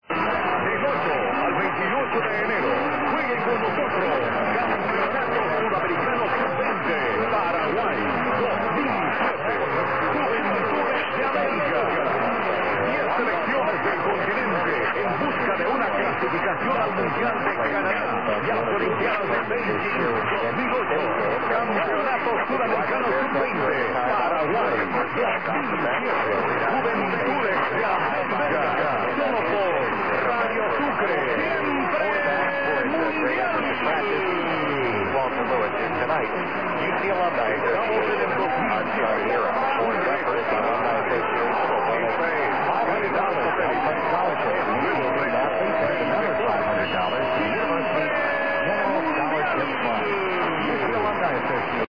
This one gets out well, and with frequent IDs, is quite easy to pick out in a